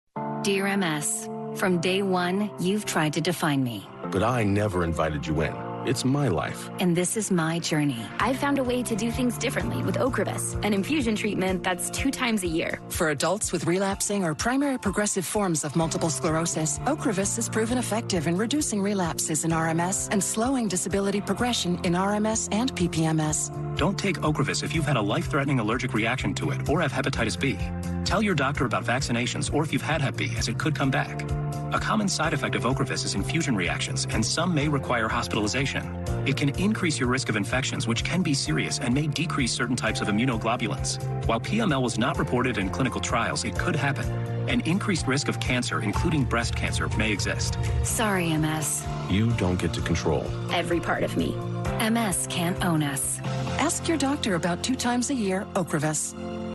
About twenty-five seconds into the advertisement the announcer lists a series of warning and disclaimers.
I eventually find out the perplexing commercial is for the prescription drug, Ocrevus.